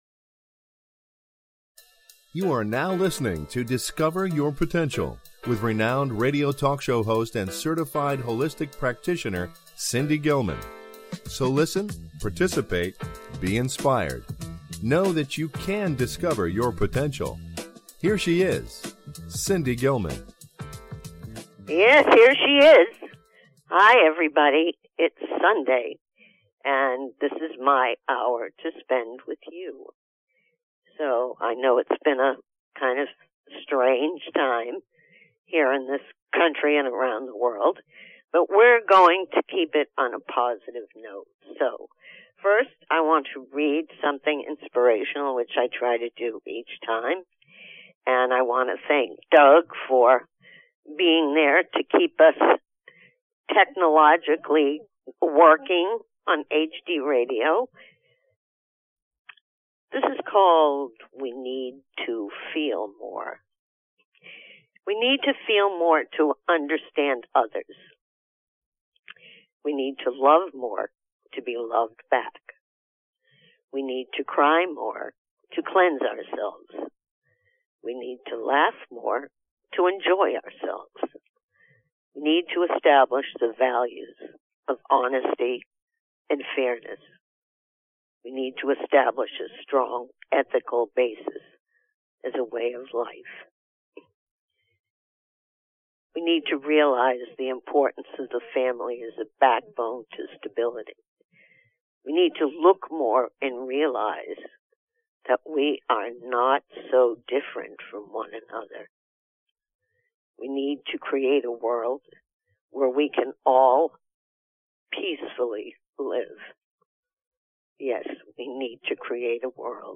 Guest, James Van Praagh